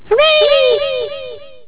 horray.au